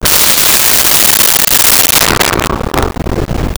Alligator Hiss 01
Alligator Hiss 01.wav